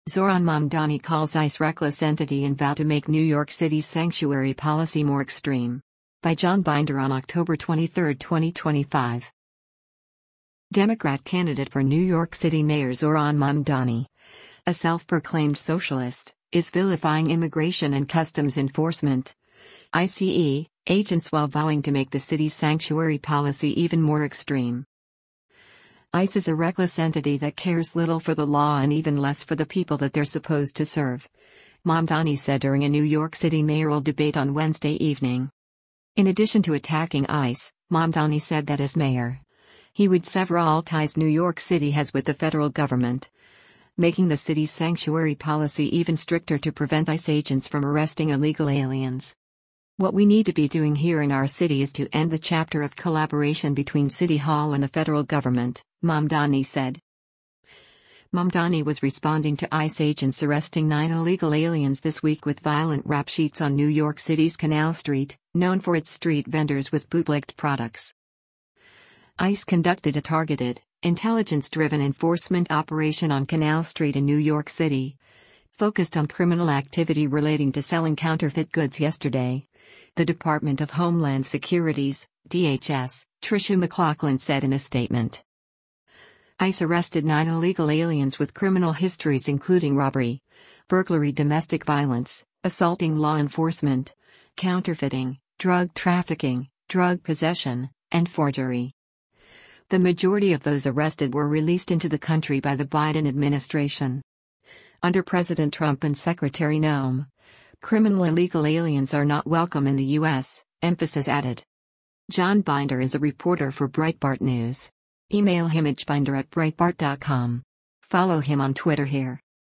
“ICE is a reckless entity that cares little for the law and even less for the people that they’re supposed to serve,” Mamdani said during a New York City mayoral debate on Wednesday evening.